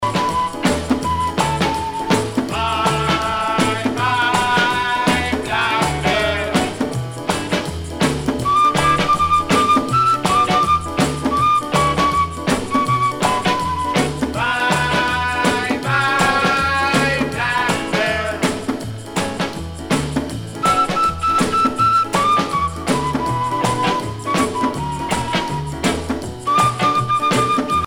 danse : twist
Pièce musicale éditée